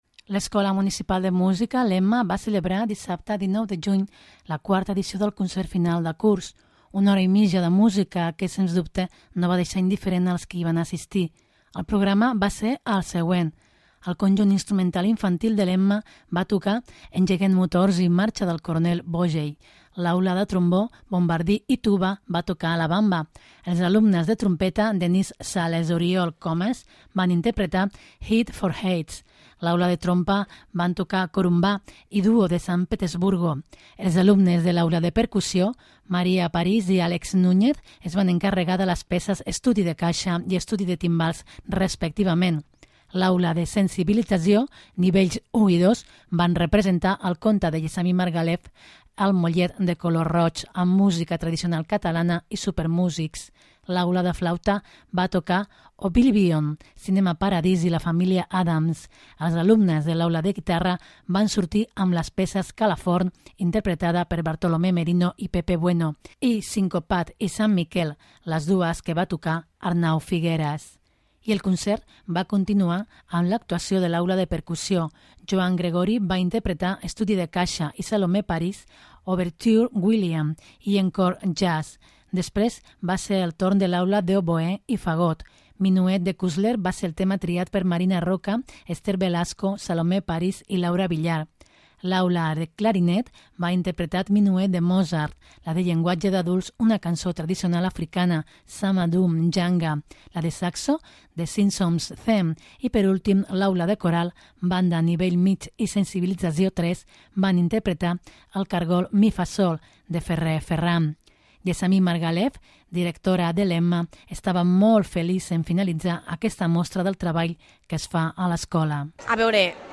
L'Escola Municipal de Música (EMMA) va celebrar dissabte 19 de juny la 4ª edició del concert final de curs.
L'Escola Municipal de Música va finalitzar el curs amb una mostra del treball dels seus 140 alumnes que van desfilar per l'escenari per meravellar el públic.